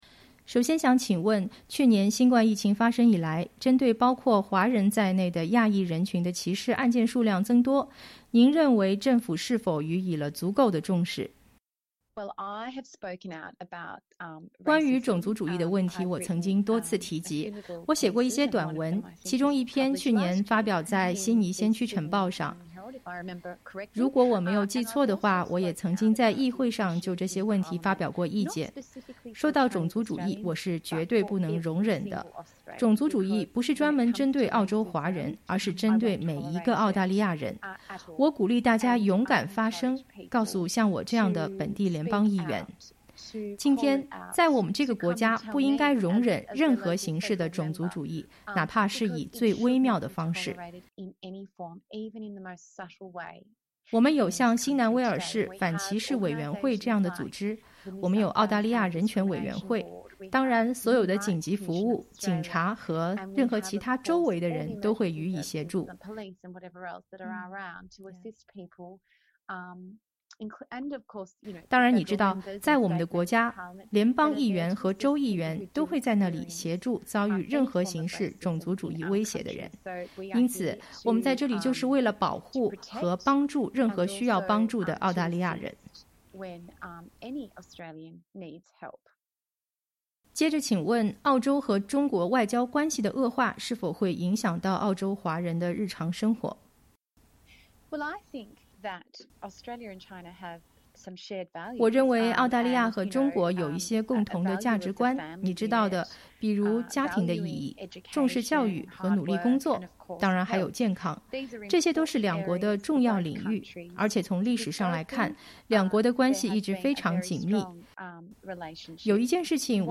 农历新年将至，悉尼Reid选区联邦议员的菲奥娜·马丁（Fiona Martin）博士前不久接收SBS中文记者采访，回应了有关种族歧视、澳中关系、心理健康、政治壁垒等澳州华人社区关心的话题。